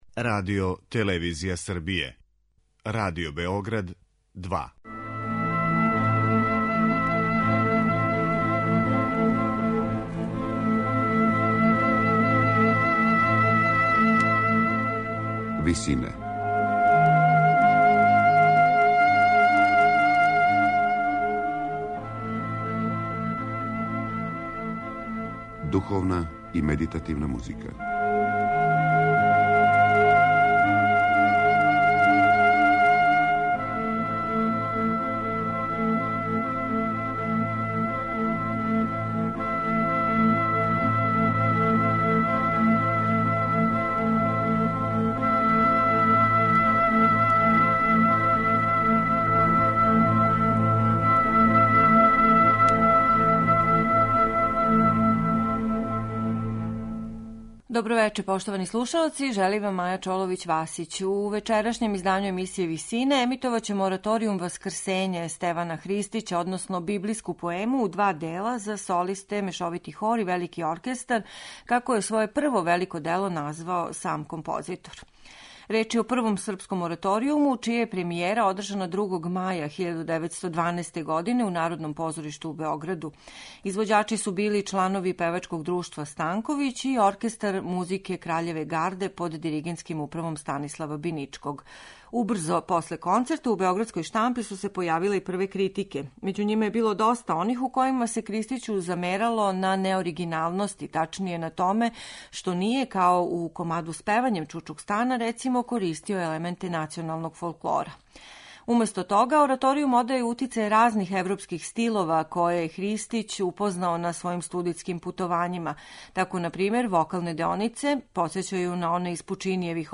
Емисија је посвећена композицији Васкрсење - библијској поеми у два дела за солисте, мешовити хор и велики оркестар, како је своје прво велико дело и први ораторијум у српској музици из 1912. године назвао Стеван Христић.